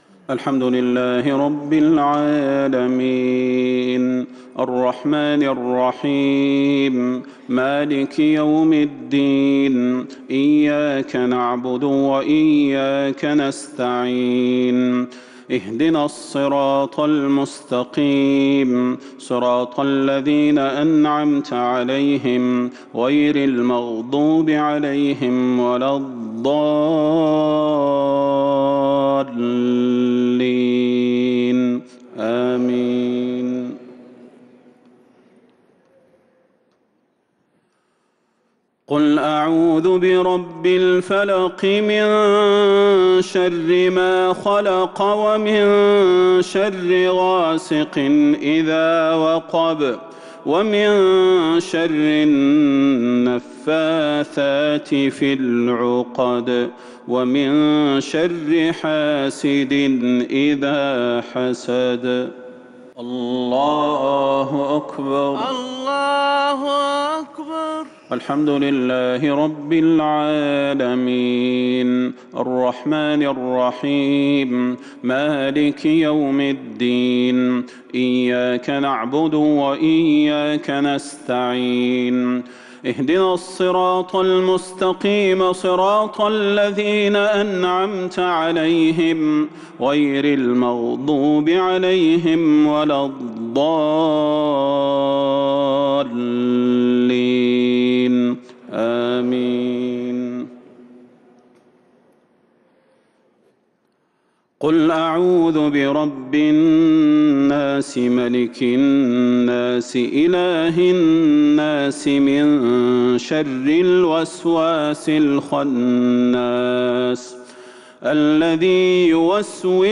صلاة الجمعة 1-2-1442 هـ سورتي الفلق و الناس | Jumu'ah prayer Surah Al-Falaq and An-Naas 18/9/2020 > 1442 🕌 > الفروض - تلاوات الحرمين